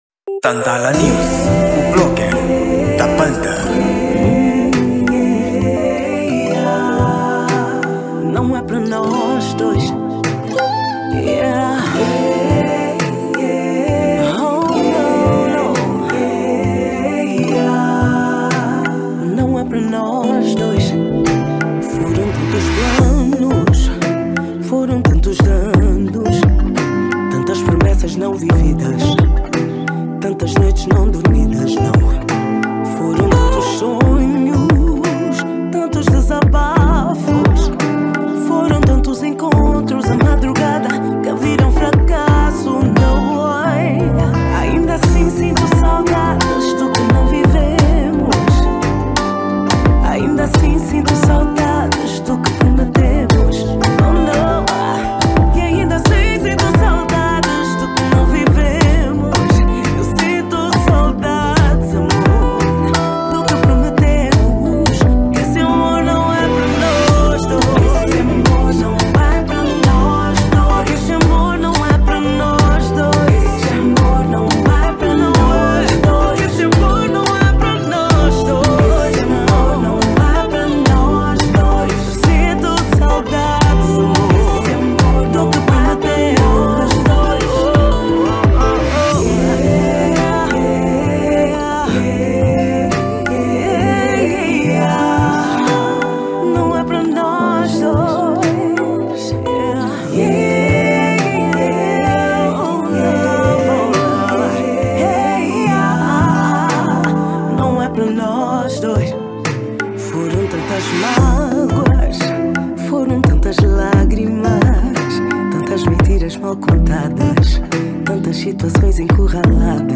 Gênero: Zouk